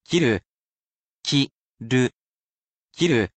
He’ll be here to help sound out these vocabulary words for you.
He’s lovely with tones, as well, and he will read each mora so you can spell it properly in kana.